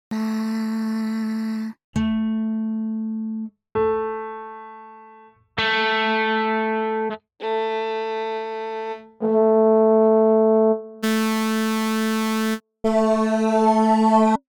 The original timbres
P-timbres.mp3